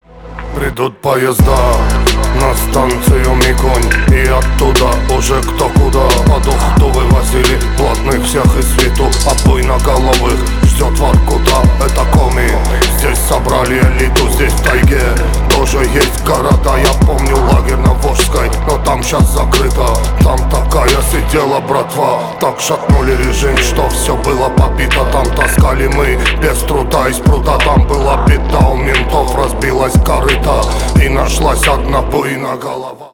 блатные
рэп